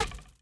arrow_hit5.wav